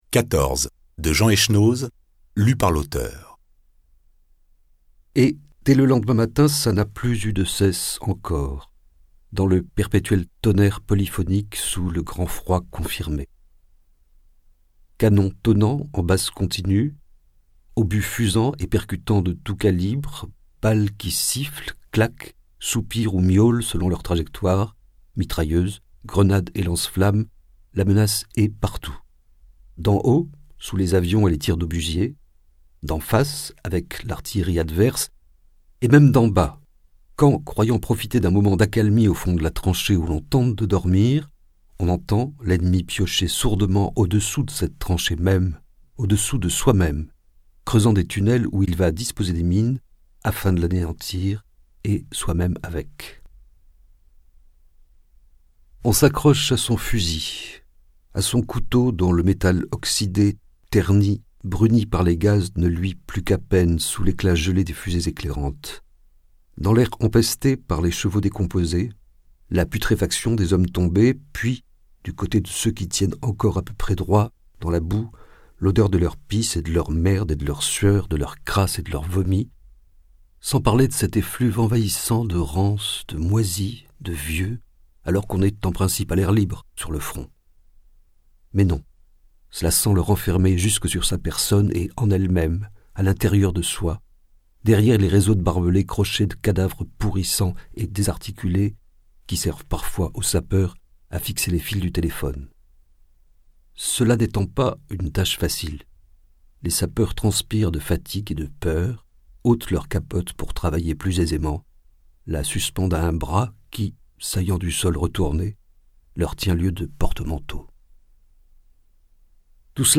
Extrait gratuit - 14 de Jean Echenoz